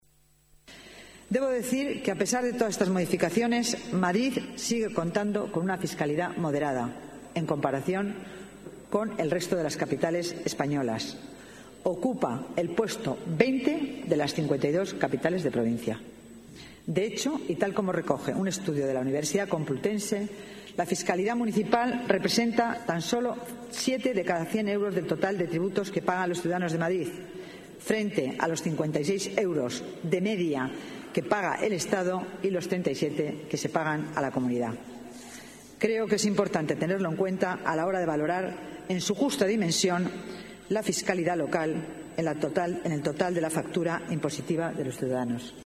Nueva ventana:Dancausa, delegada de Hacienda. Ordenanzas fiscales